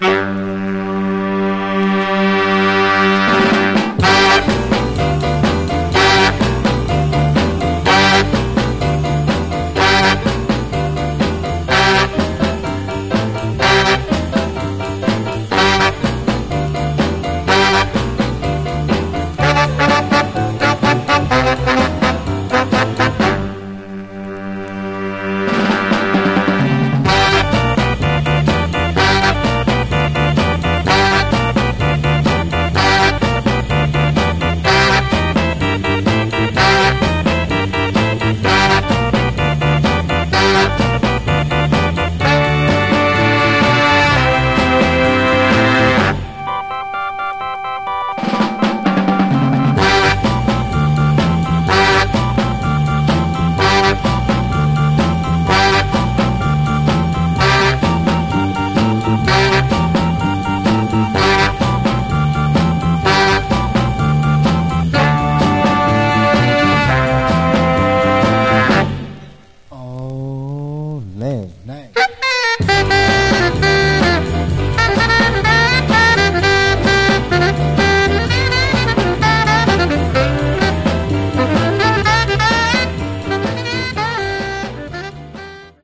Genre: Soul Instrumentals